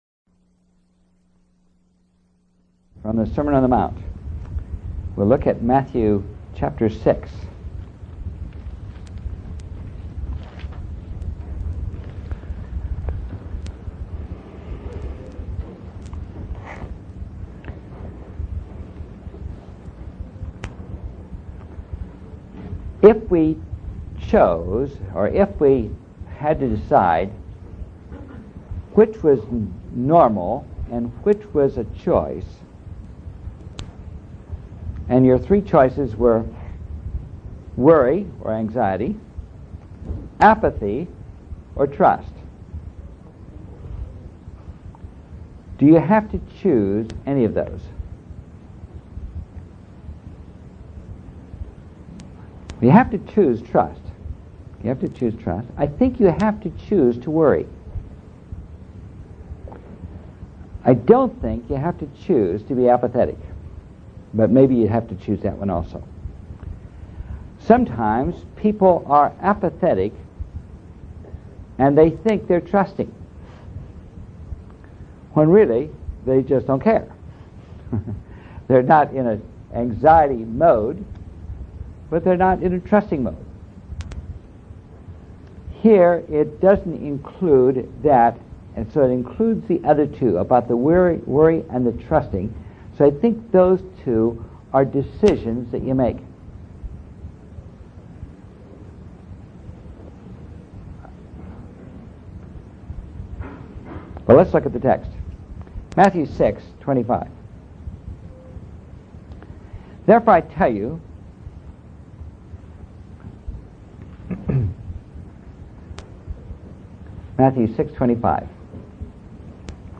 In this sermon, the speaker reflects on their personal experience of memorizing and knowing Bible verses, and how they mistakenly believed that knowing the Bible made them more spiritual than others.